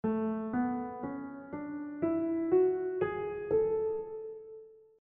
• Major Scale (A Ionian): W - W - H - W - W - W - H (A – B – C# – D – E – F# – G# – A)
A Major Scale